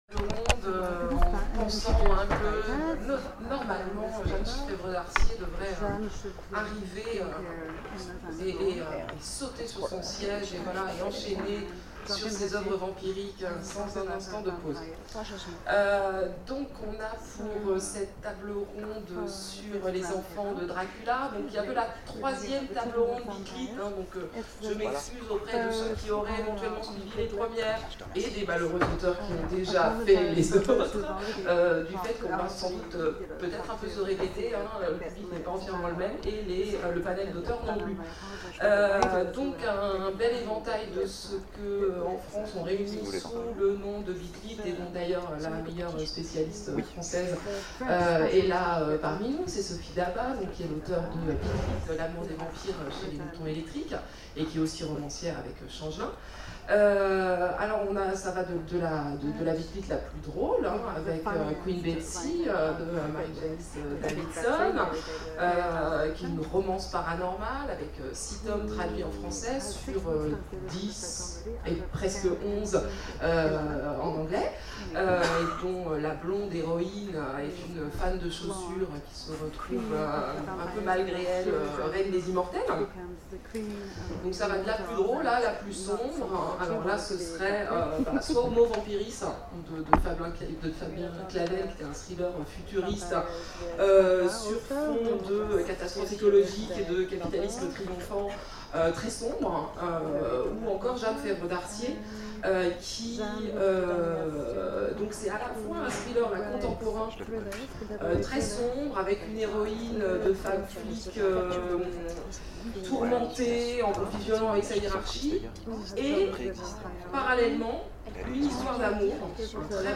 Imaginales 2012 : Conférence les enfants de Dracula....